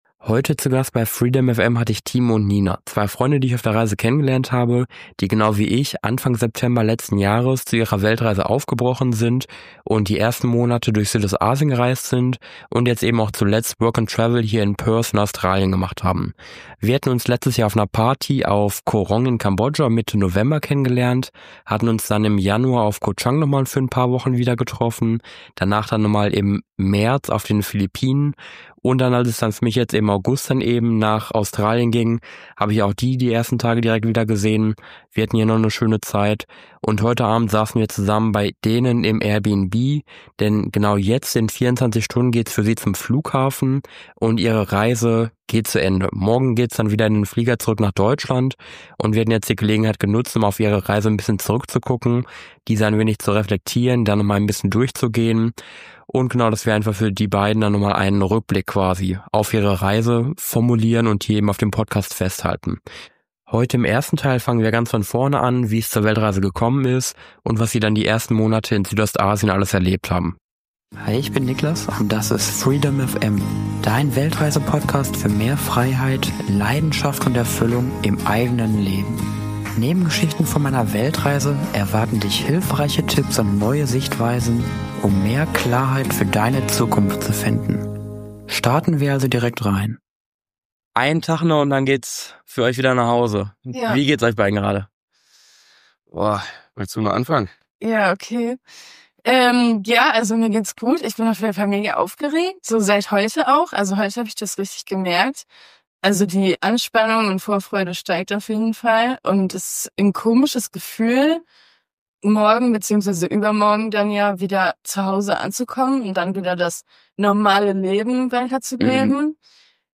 Am vorletzten Abend haben wir gemeinsam hier in Perth dieses Interview geführt, um nochmal in einem großen Rückblick in Erinnerungen zu schwelgen.